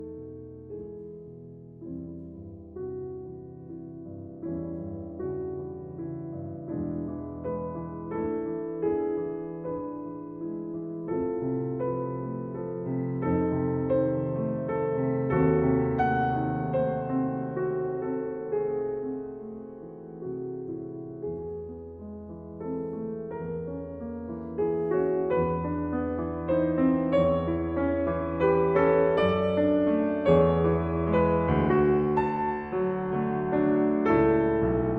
Classical Piano Romantic Era
Жанр: Классика